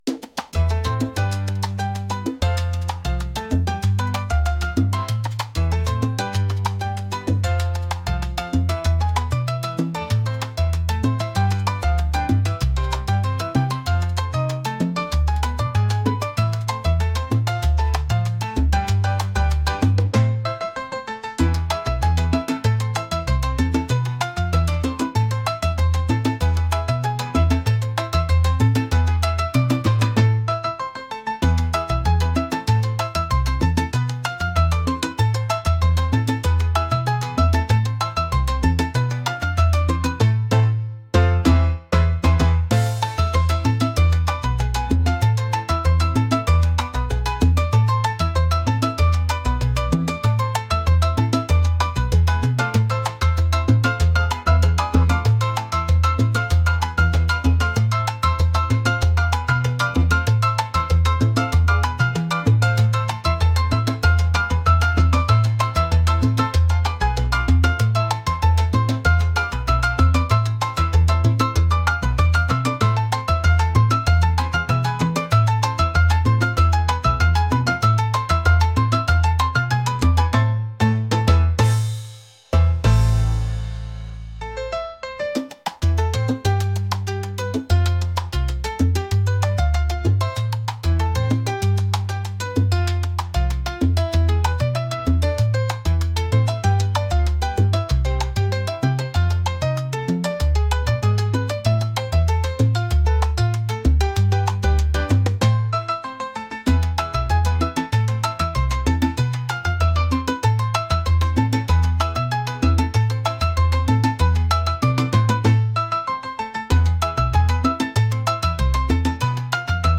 groovy | latin